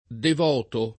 vai all'elenco alfabetico delle voci ingrandisci il carattere 100% rimpicciolisci il carattere stampa invia tramite posta elettronica codividi su Facebook devoto [ dev 0 to ] (antiq. divoto [ div 0 to ]) agg. e s. m. — sim. i cogn.